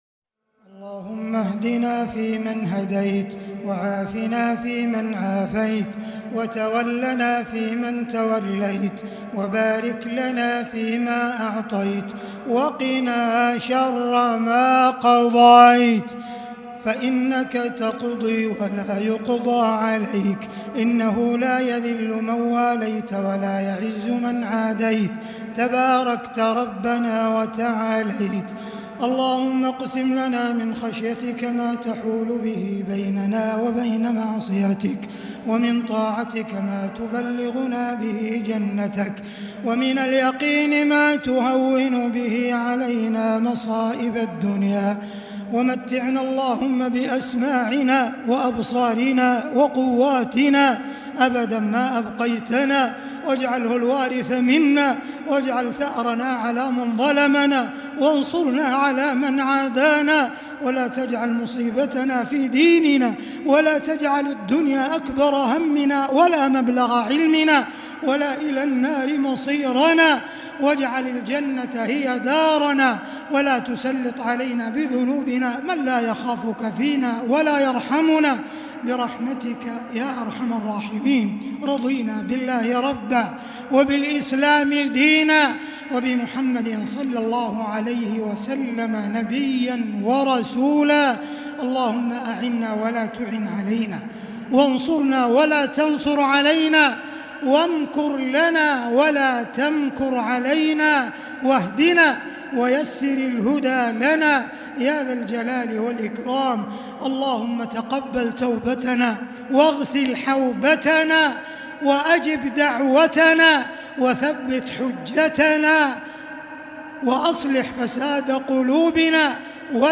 دعاء القنوت ليلة 23 رمضان 1441هـ > تراويح الحرم المكي عام 1441 🕋 > التراويح - تلاوات الحرمين